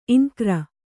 ♪ iŋkra